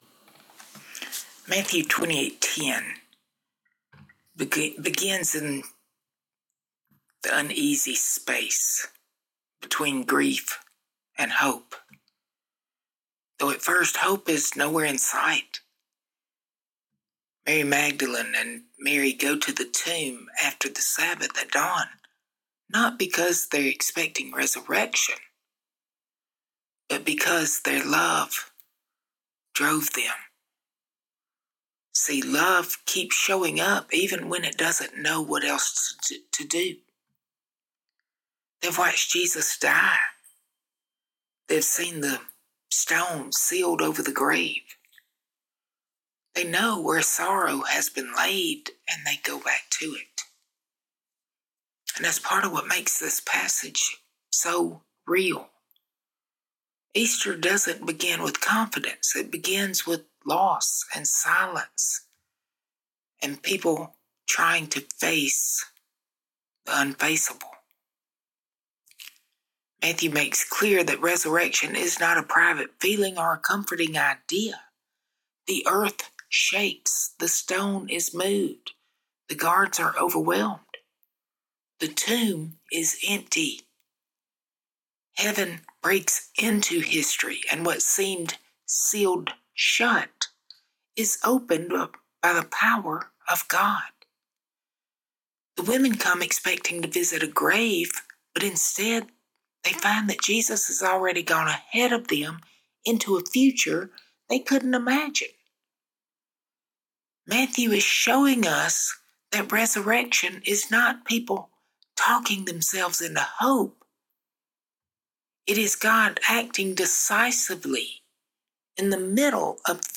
He Is Not Here is an Easter sermon on Matthew 28:1-10 about the empty tomb, resurrection hope, and Christ going ahead of us.